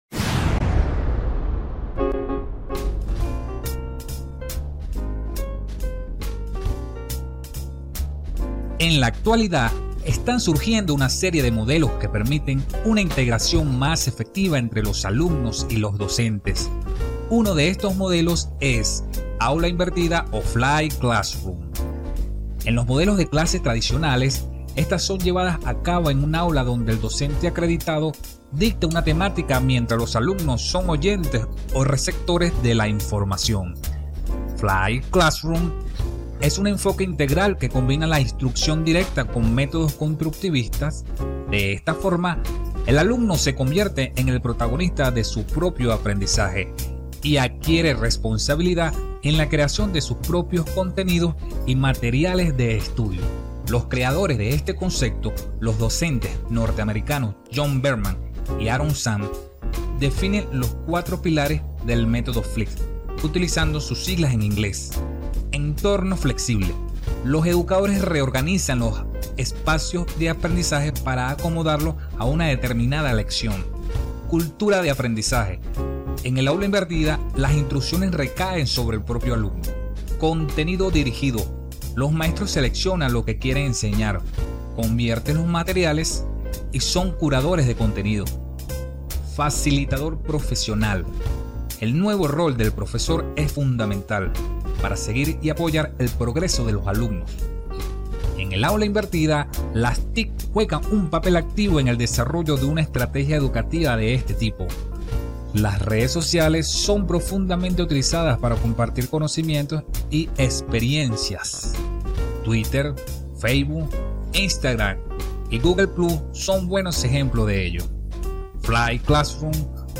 Voz versatil y adaptable en velocidad y tono
spanisch Südamerika
Sprechprobe: eLearning (Muttersprache):